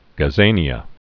(gə-zānē-ə, -nyə)